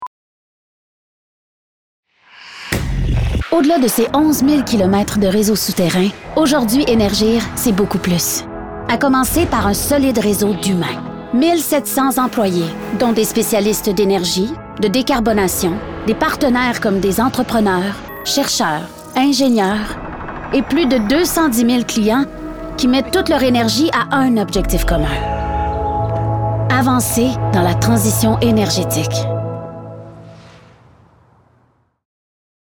voix